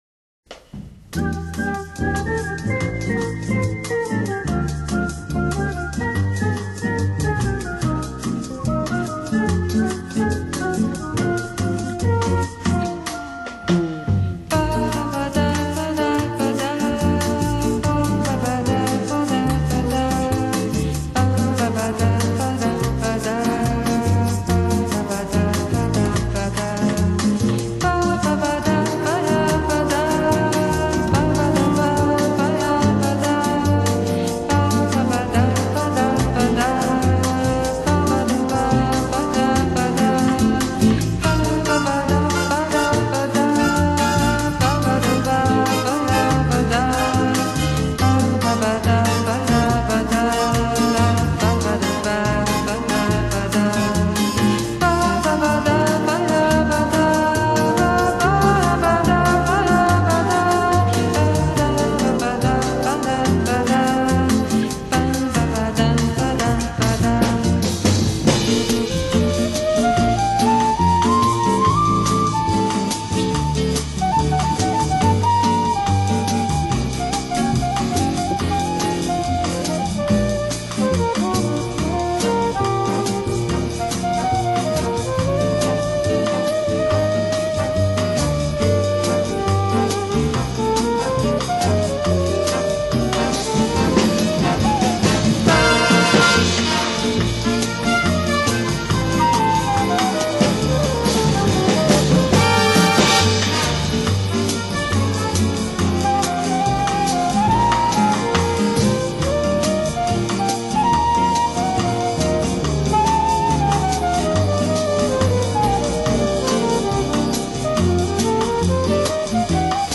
Jazz, Vocal Jazz